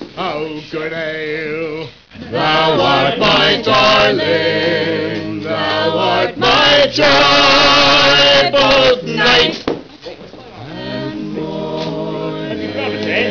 vocals, violin
vocals, bodhran
-- vocals, guitar, pennywhistle
vocals, octave mandolin, hammered dulcimer